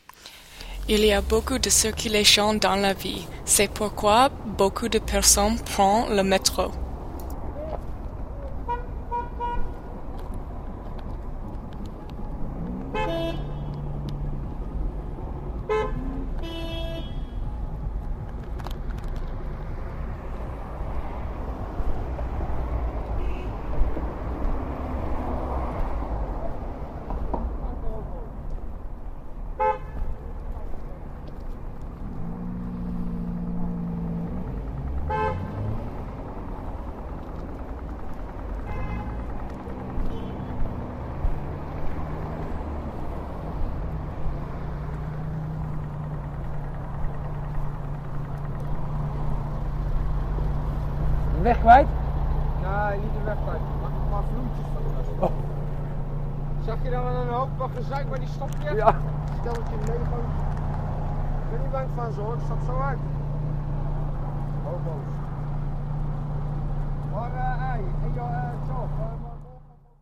La circulation